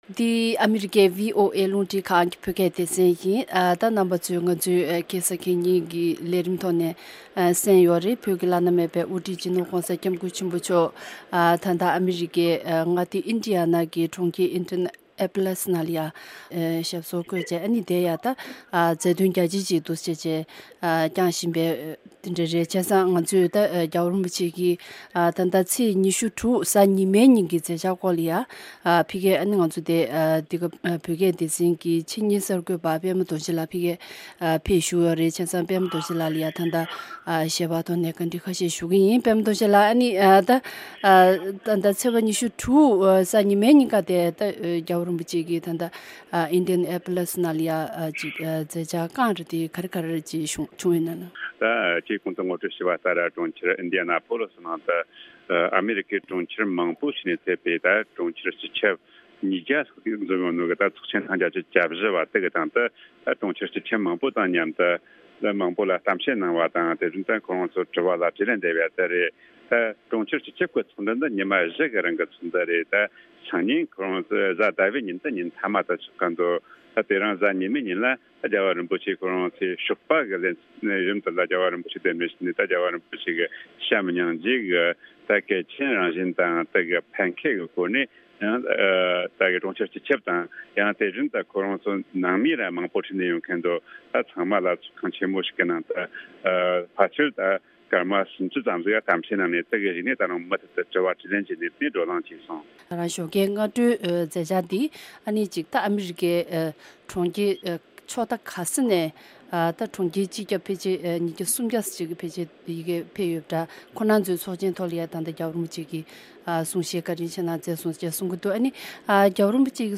Dalai Lama Addresses Conference of Mayors in Downtown Indianapolis (In Tibetan)